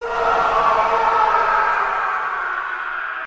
Screamer Halloween Sound Button: Unblocked Meme Soundboard
Play the iconic Screamer Halloween sound button for your meme soundboard!